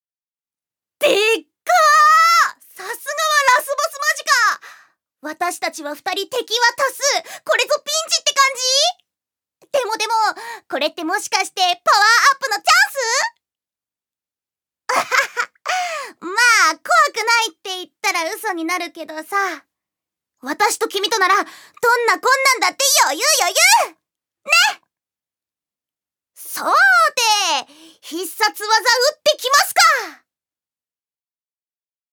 ジュニア：女性
セリフ２